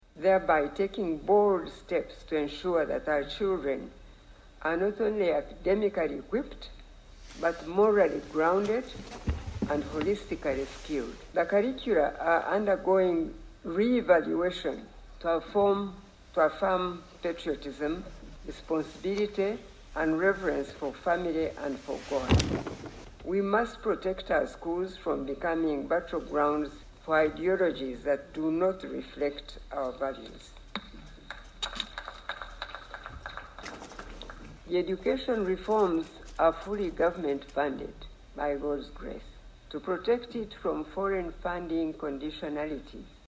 In the meeting, Janet Museveni warned of growing global influences that threaten to erode African cultural identity and family structures under the guise of aid and development.
Mrs. Museveni, speaking with the conviction of a wife, mother, and grandmother, emphasised the urgency of reclaiming the continent’s educational, cultural and spiritual foundations from foreign interests.